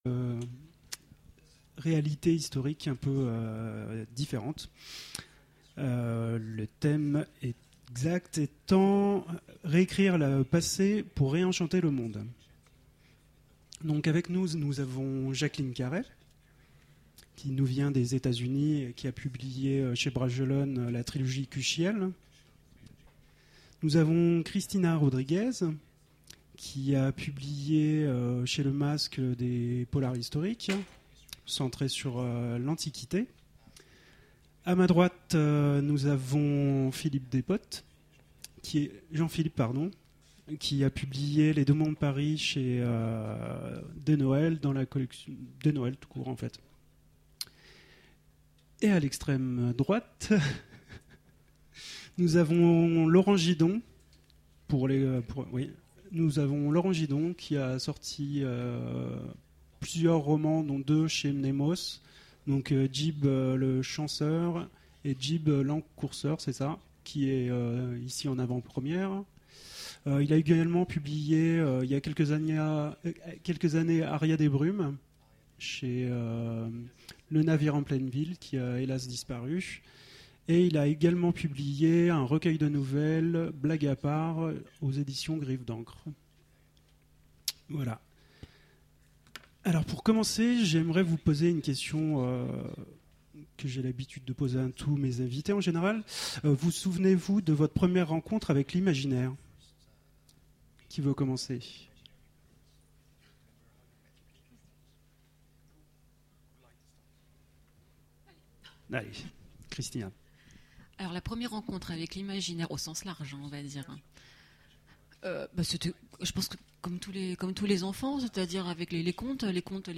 Voici l'enregistrement de la conférence Réécrire le passé... pour ré-enchanter le monde ! aux Imaginales 201